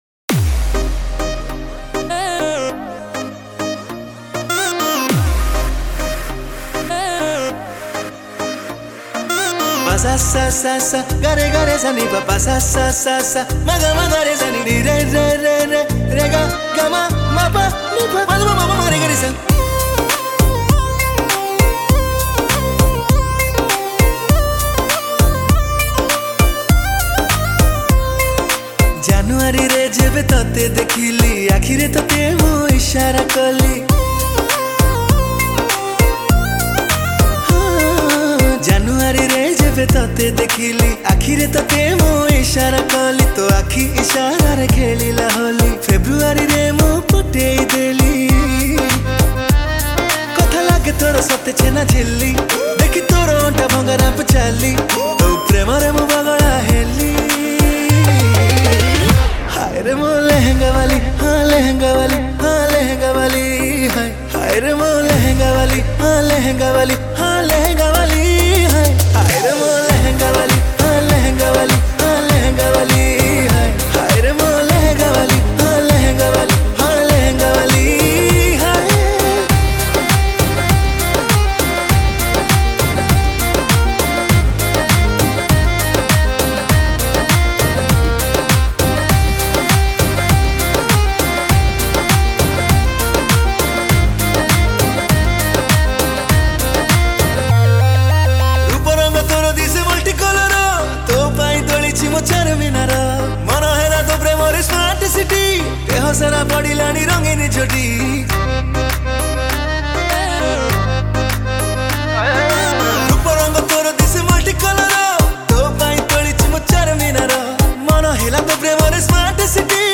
Song Type :Dance